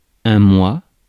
Ääntäminen
Ääntäminen France: IPA: [ɛ̃ mwɑ] Tuntematon aksentti: IPA: /mwa/ Haettu sana löytyi näillä lähdekielillä: ranska Käännös Konteksti Ääninäyte Substantiivit 1. month UK UK US 2. moon kirjakieli UK US UK Suku: m .